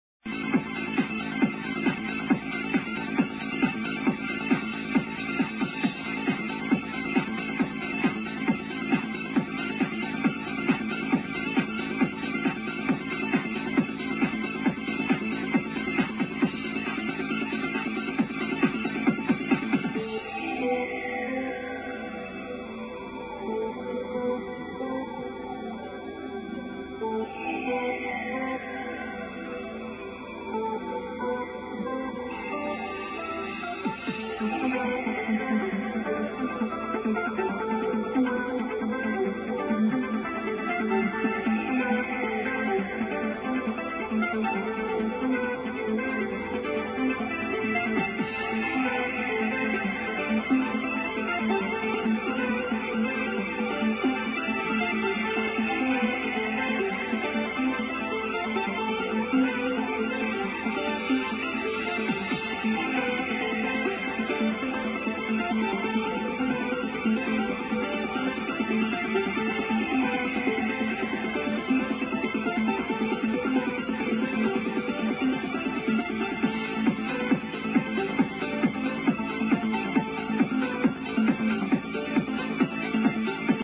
sorry for the shitty quailty, but this is a KILLER tune